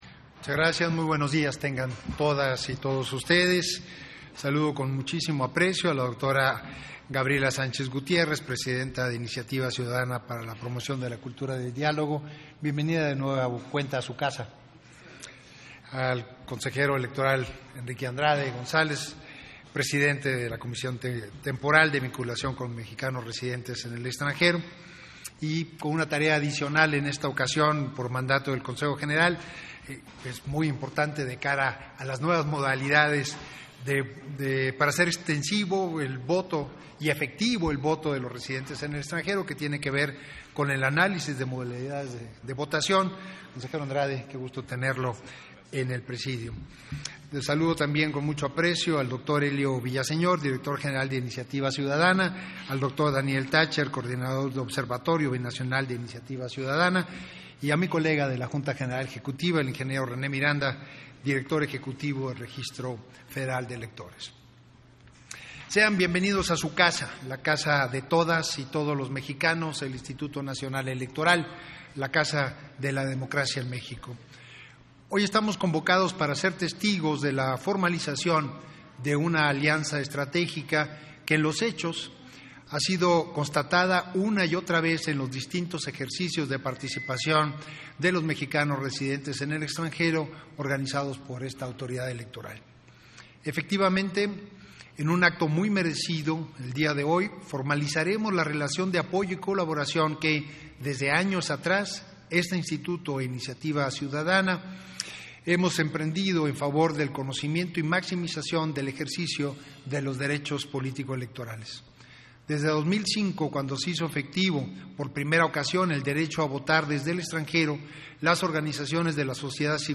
Intervención de Edmundo Jacobo, en la firma de Convenio de Colaboración entre el INE e Iniciativa Ciudadana
VERSIÓN ESTENOGRÁFICA DE LA INTERVENCIÓN DEL SECRETARIO EJECUTIVO DEL INSTITUTO NACIONAL ELECTORAL, EDMUNDO JACOBO MOLINA, DURANTE LA FIRMA DE CONVENIO DE COLABORACIÓN ENTRE EL INE Y LA ORGANIZACIÓN INICIATIVA CIUDADANA PARA LA PROMOCIÓN DE LA CULTURA DEL DIÁLOGO, REALIZADA EN EL LOBBY DEL AUDITORIO DE LA INSTITUCIÓN